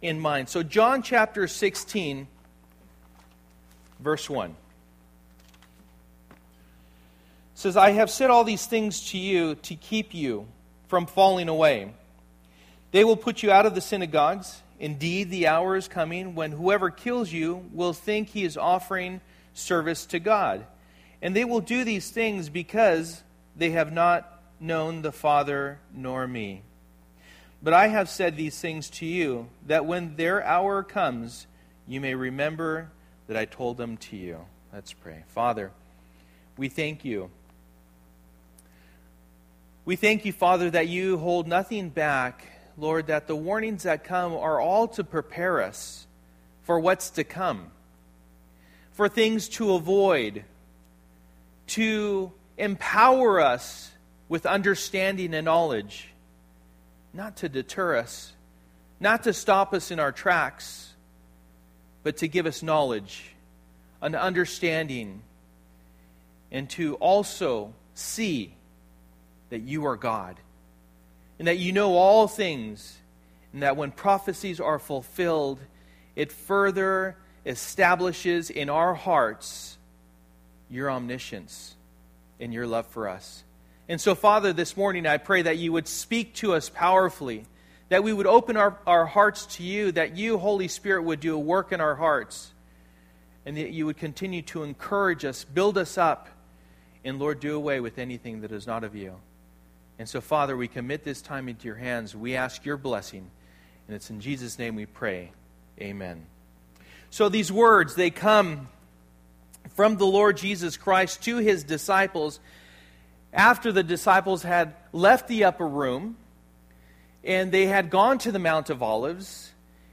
John 16:1-15 Service: Sunday Morning %todo_render% « Abide in Me The Preparation of the Disciples